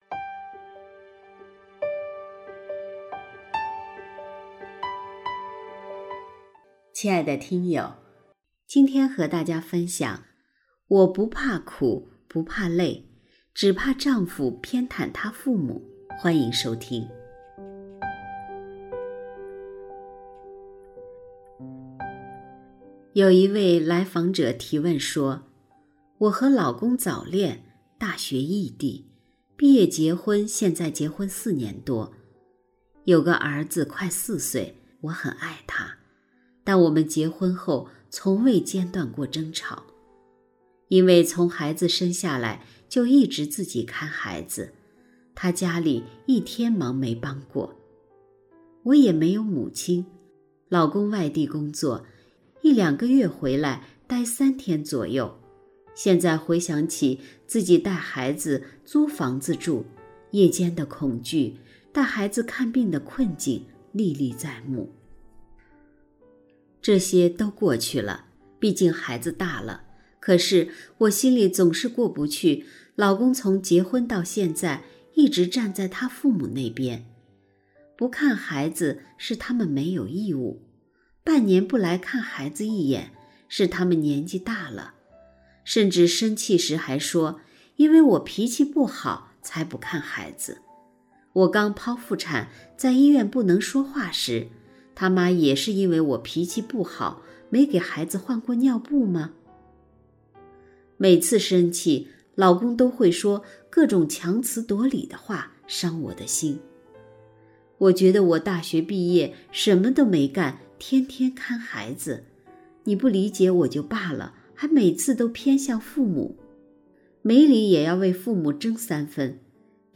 首页 > 有声书 > 婚姻家庭 > 单篇集锦 | 婚姻家庭 | 有声书 > 我不怕苦不怕累，只怕丈夫偏袒他父母